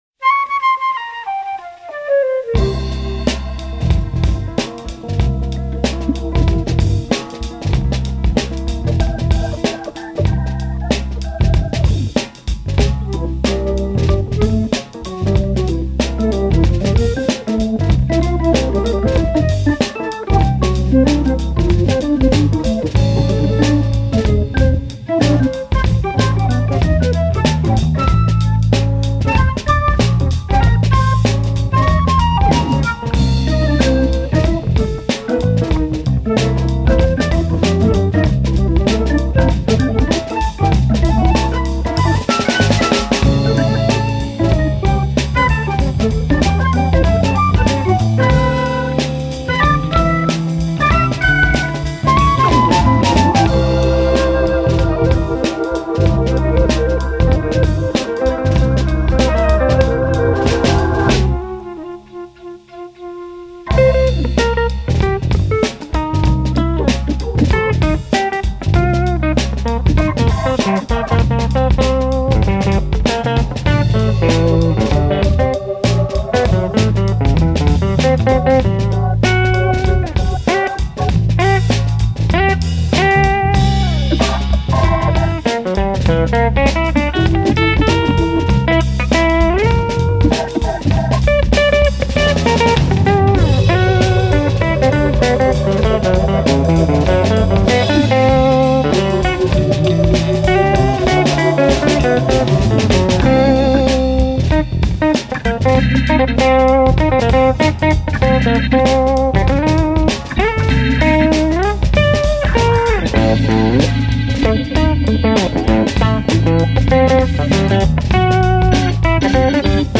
Recorded at the Art Institute of Seattle